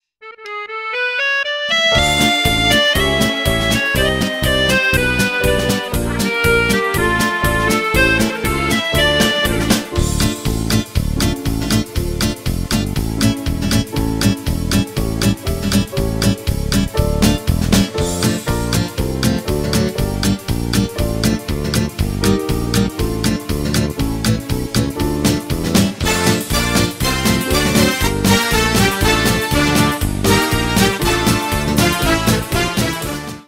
оркестр , военные
ретро